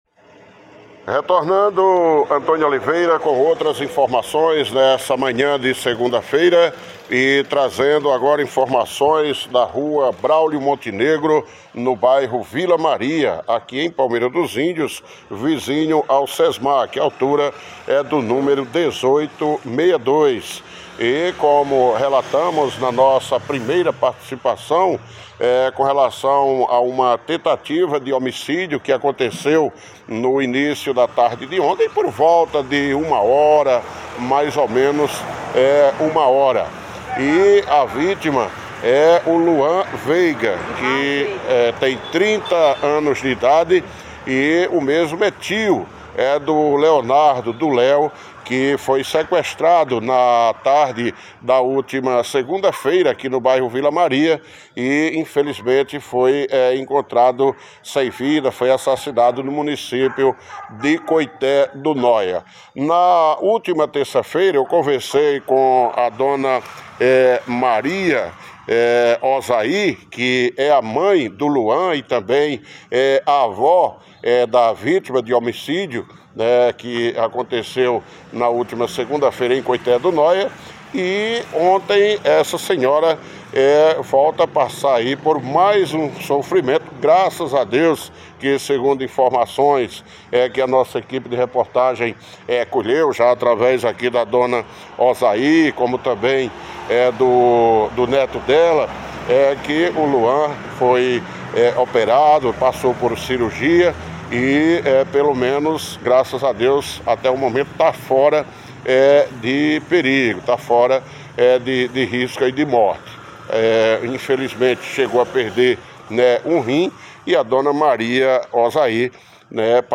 Entrevista-3.mp3